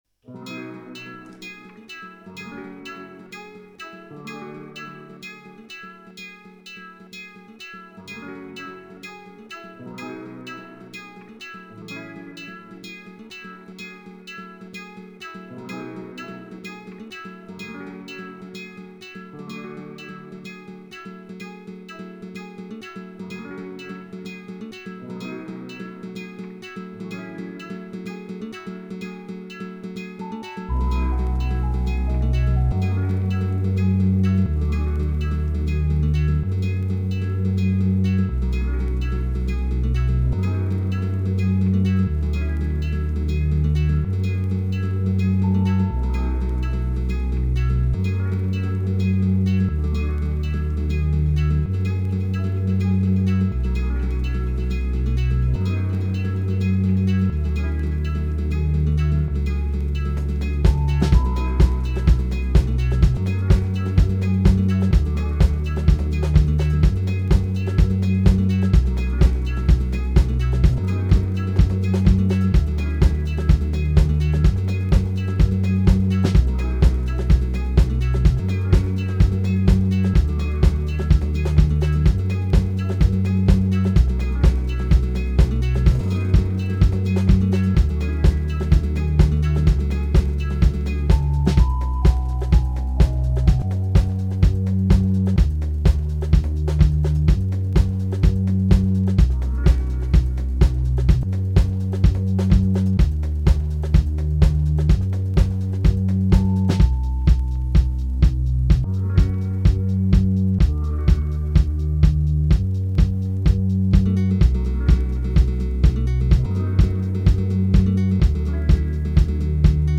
Some homemade samples into DT2